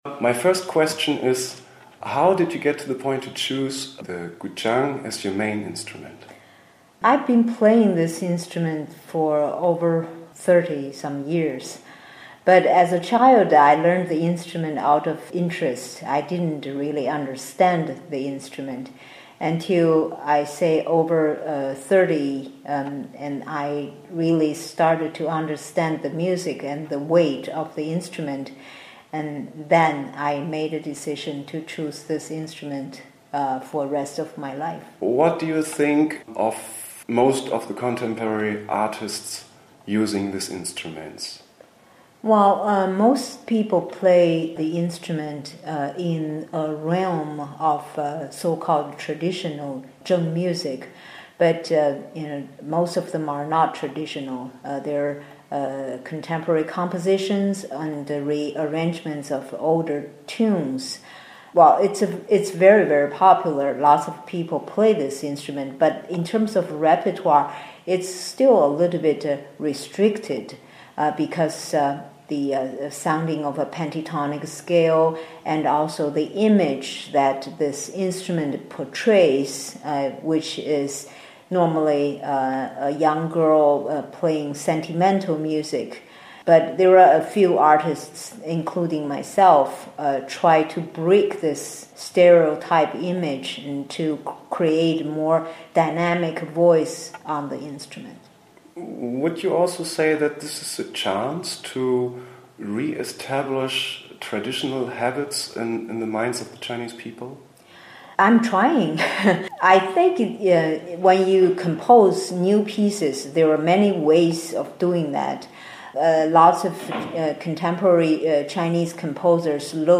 interviewmh.mp3